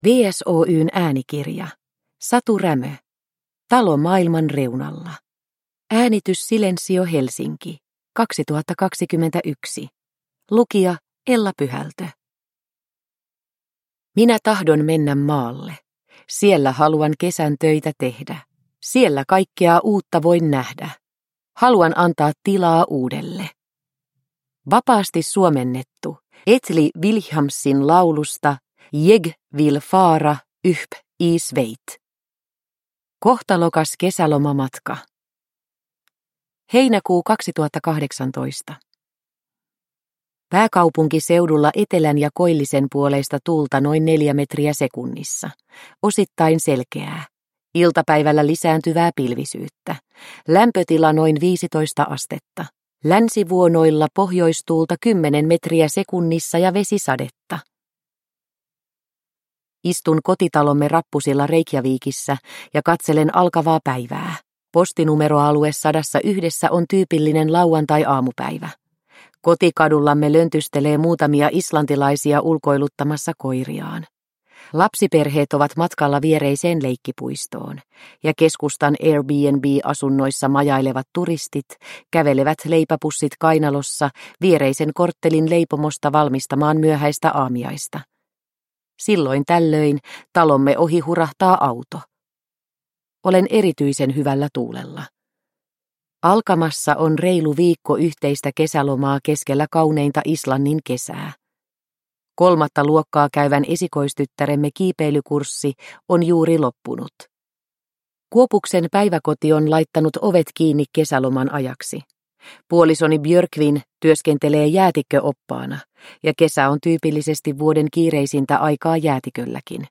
Talo maailman reunalla – Ljudbok